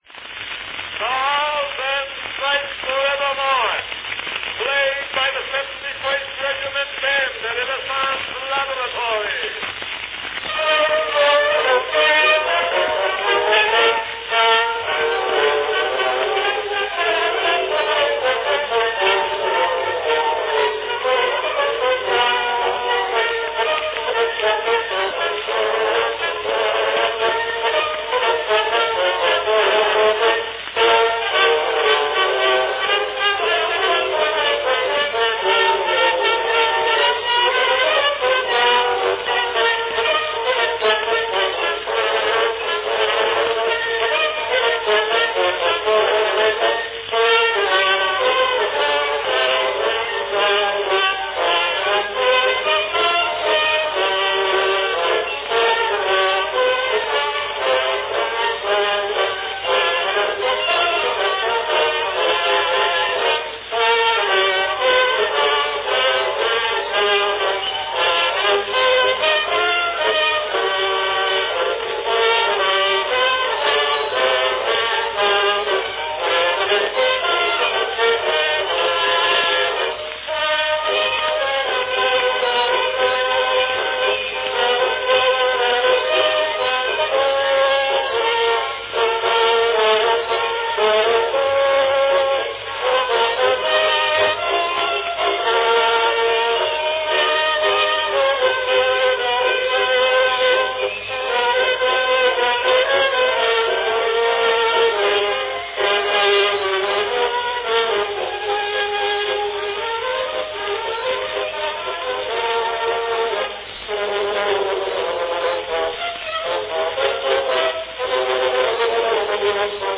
An early recording (perhaps the earliest) of Sousa's great Stars and Stripes Forever March.
Category Band
Performed by 71st Regiment Band